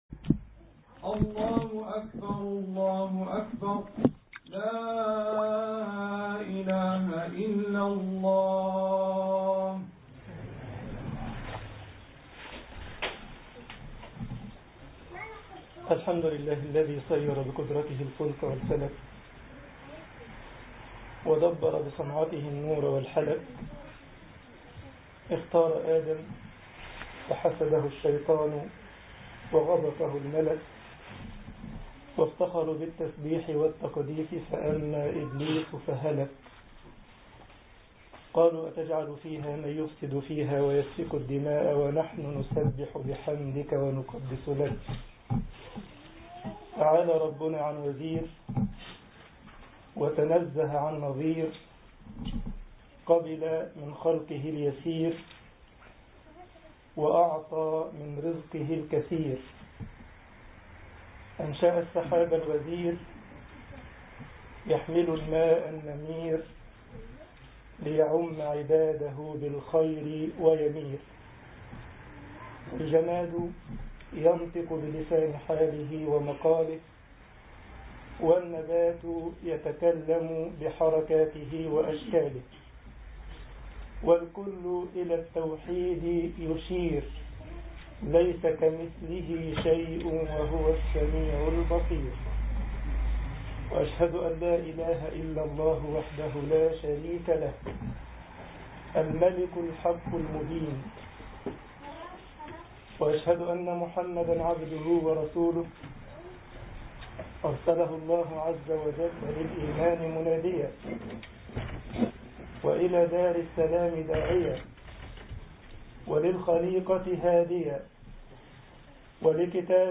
خطبة الجمعة
جمعية الشباب المسلمين بسلزبخ - ألمانيا